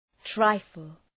Προφορά
{‘traıfəl}